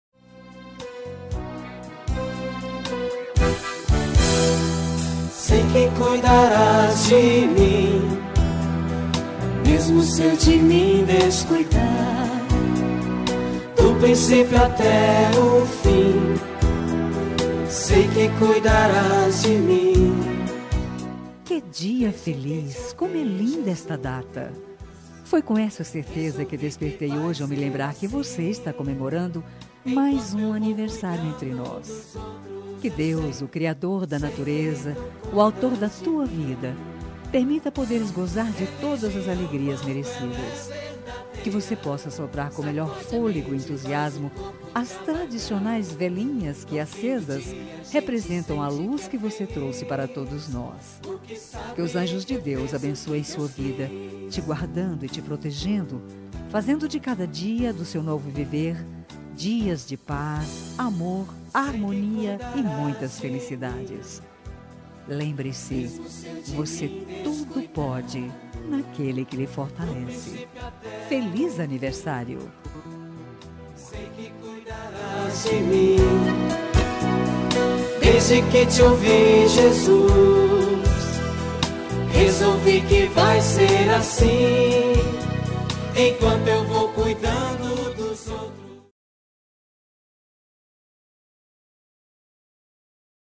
Aniversário Religioso – Voz Feminina – Cód: 34897